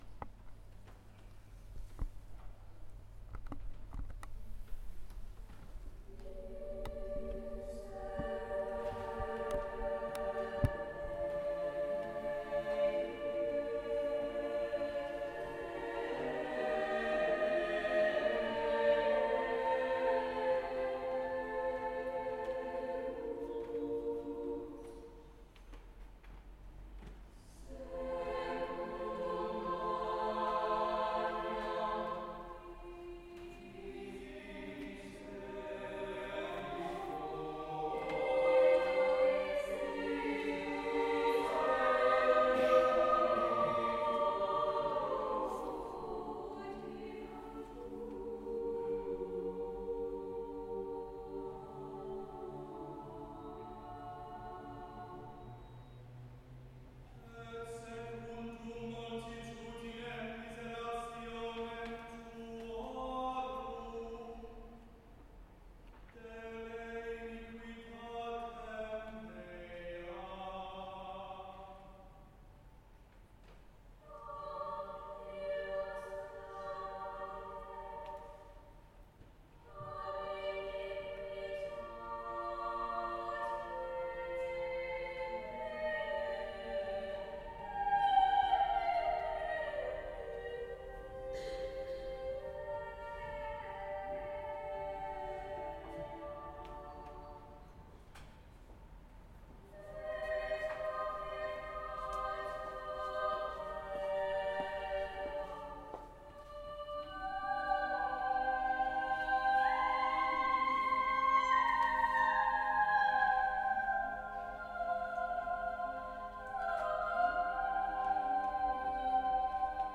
CHAMBER CHOIR Our auditioned, a capella mixed voiced choir sings early church music by composers such as Purcell and Tallis.
Click here to hear to hear a recording of the Chamber Choir performing ‘Miserere’ by Gregorio Allegri: